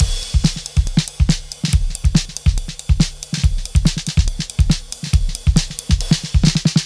ride cymbal break 140 BPM slice markers.wav